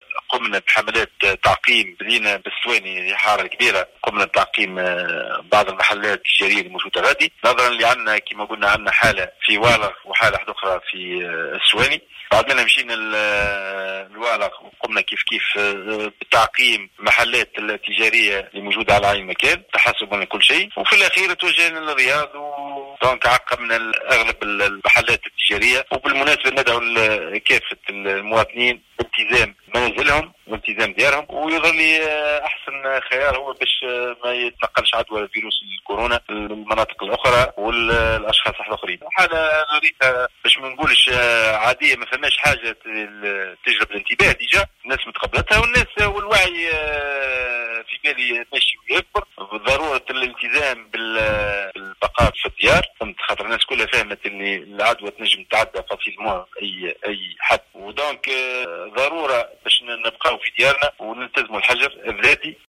أكد رئيس الدائرة البلدية بالرياض جربة محسن بن عايد في تصريح لمراسلة الجوهرة "اف ام" أن يتم حاليا القيام بحملات تعقيم لعدد من المنشات في الحارة الكبيرة و الصغيرة بجزيرة جربة للحد من انتشار فيروس الكورونا.